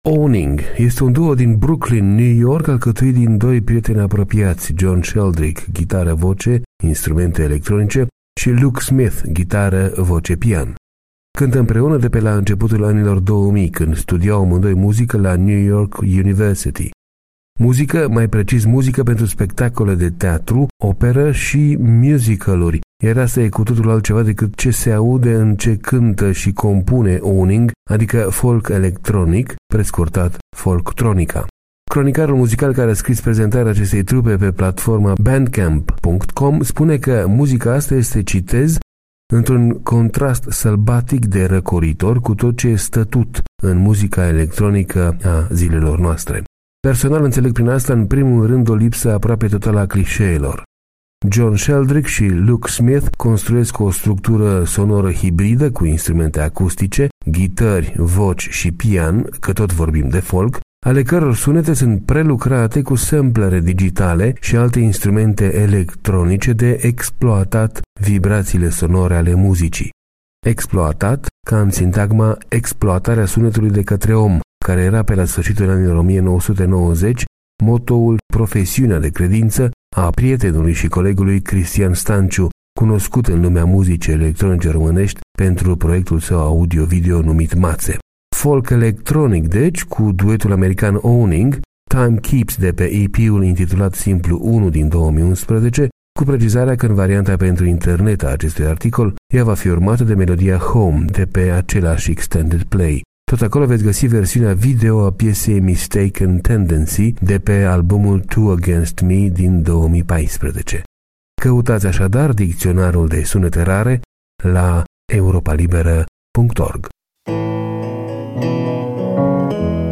ghitară, voce, instrumente electronice
ghitară, voce, pian.
adică folk electronic, prescurtat folk-tronica.